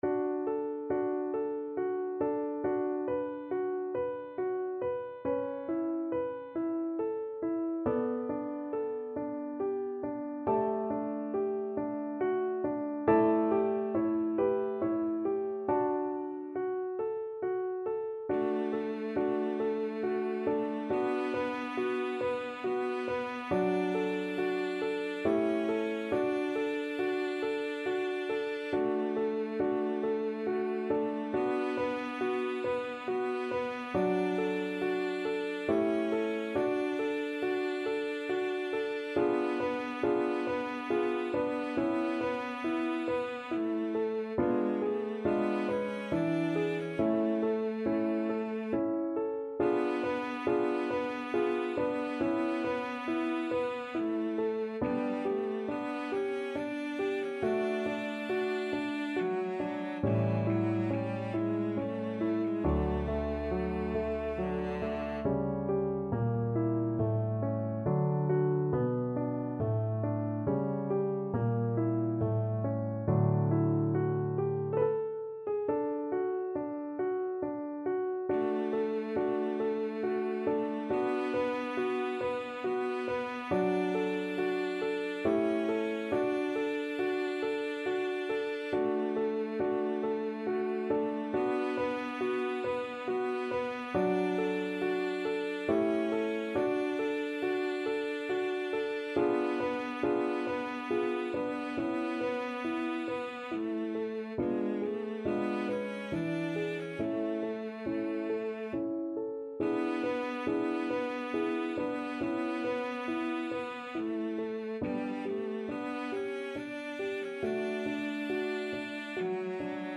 Cello
D major (Sounding Pitch) (View more D major Music for Cello )
3/8 (View more 3/8 Music)
= 69 Langsam
Classical (View more Classical Cello Music)